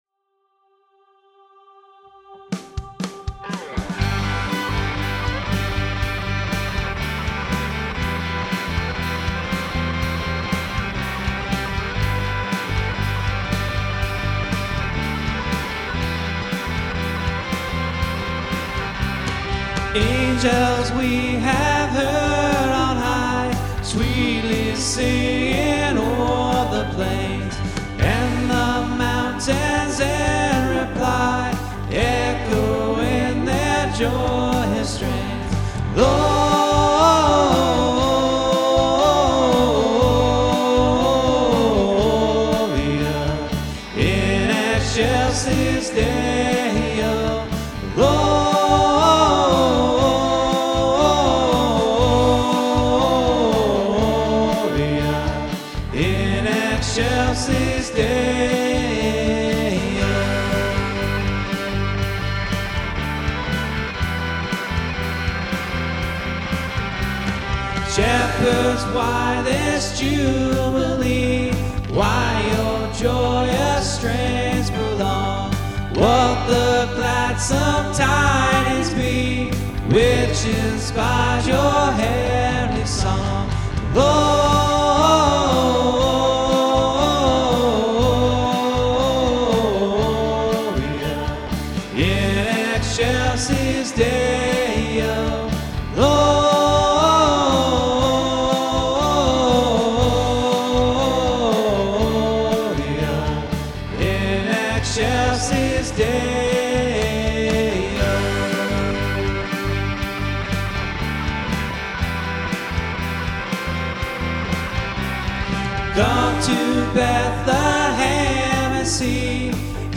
LISTEN TO THE SOME CHRISTMAS FAVOURITES RECORDED BY OUR WORSHIP TEAM!